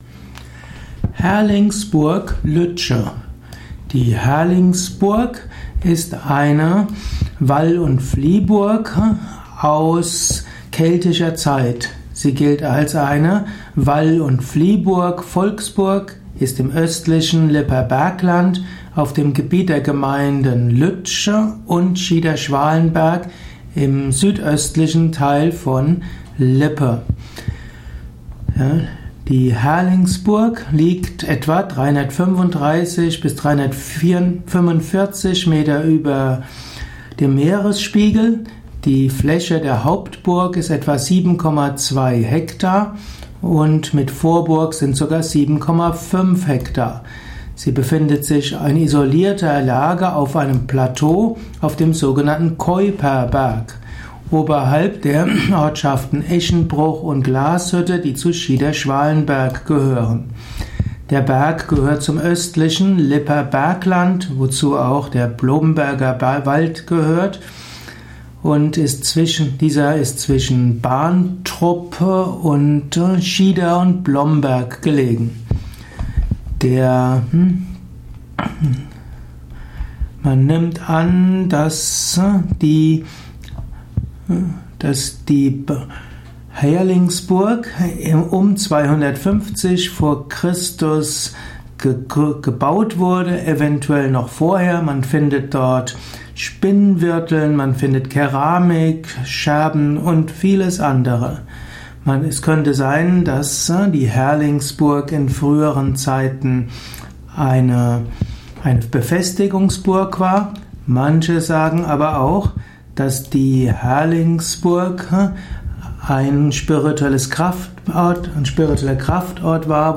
Kurzvortrag über Herlingsburg Lügde, früher und heute. Dies ist die Tonspur eines Videos.